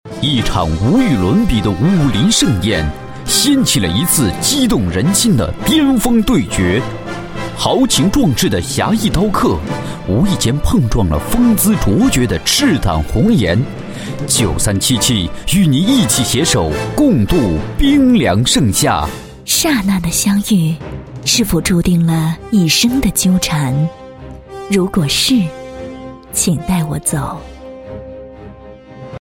普通话一级甲等